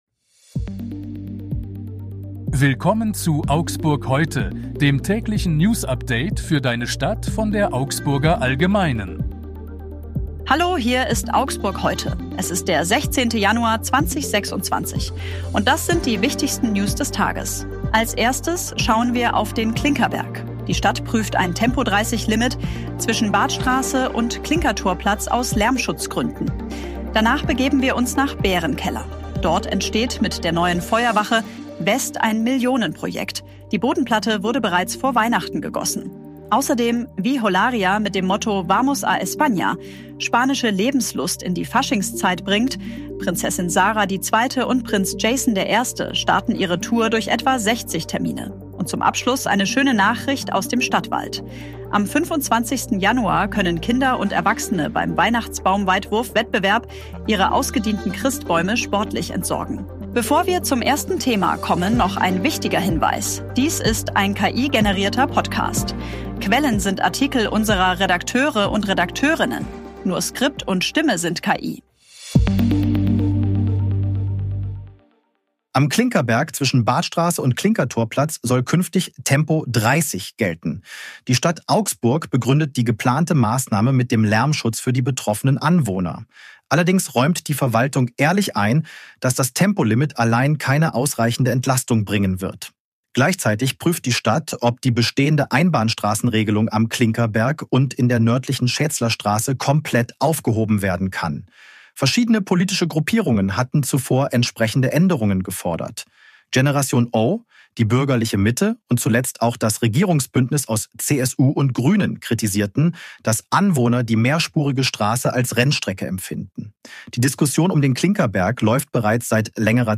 Redakteurinnen. Nur Skript und Stimme sind KI.